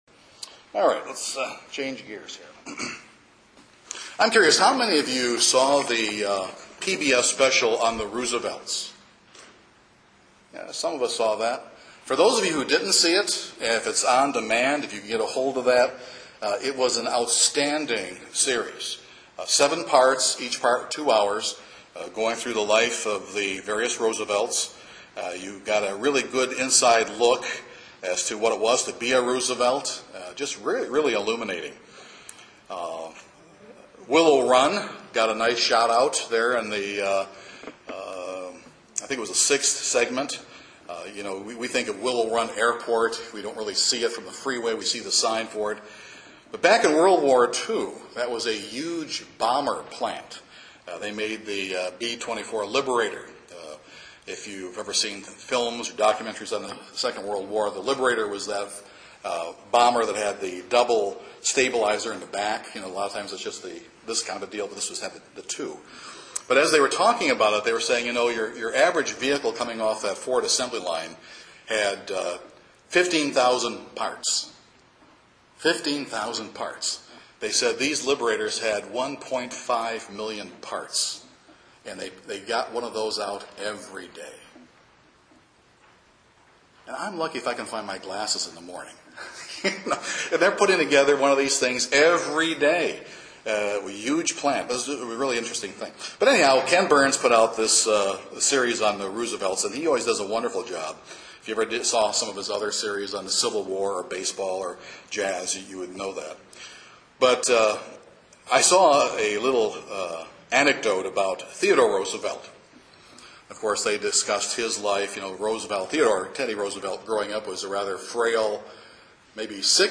But what is godly Christian zeal? This sermon will examine what godly Christian zeal isn’t and what it is.